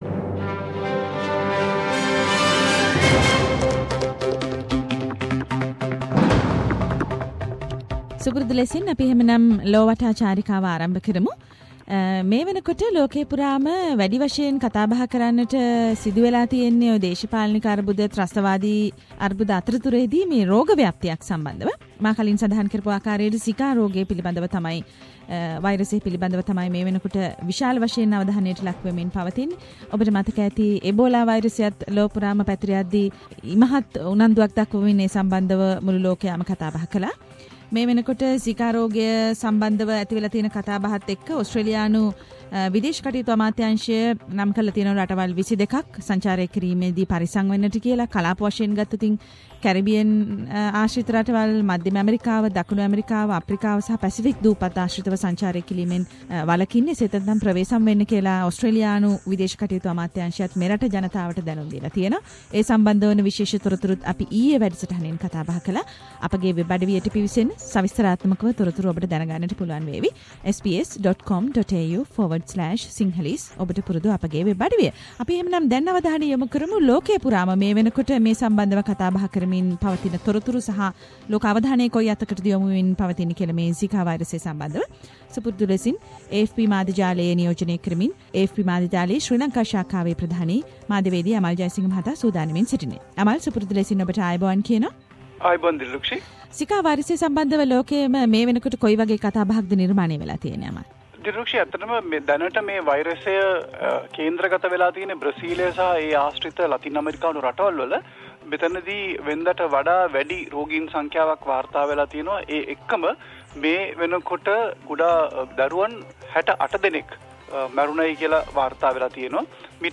Weekly world news highlights